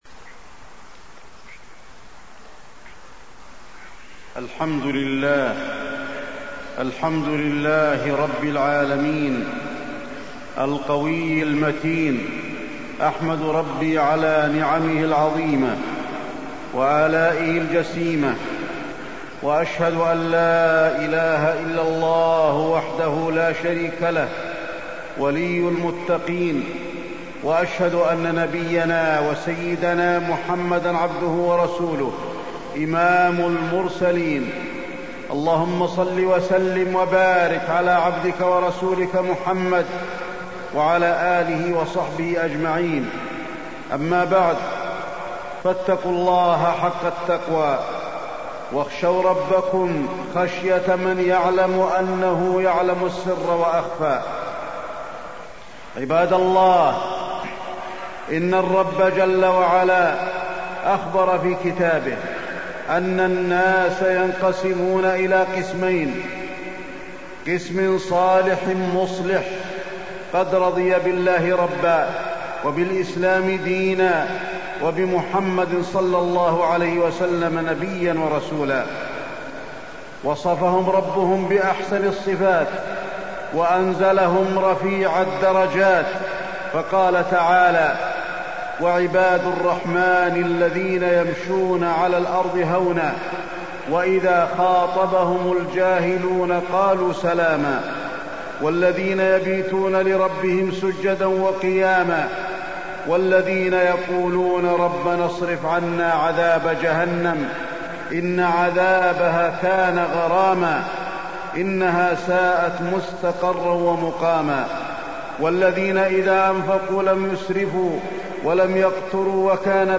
تاريخ النشر ٤ ربيع الأول ١٤٢٥ هـ المكان: المسجد النبوي الشيخ: فضيلة الشيخ د. علي بن عبدالرحمن الحذيفي فضيلة الشيخ د. علي بن عبدالرحمن الحذيفي الأحداث الأخيرة